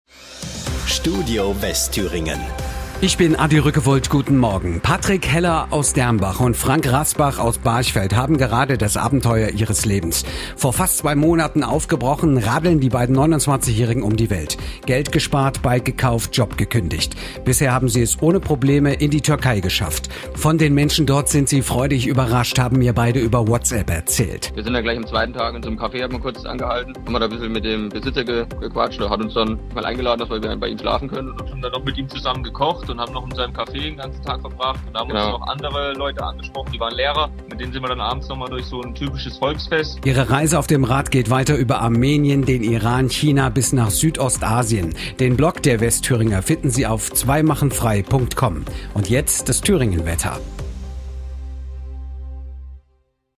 Unser Zusammenschnitt  lief bereits mehrmals in den Nachrichten im Radio.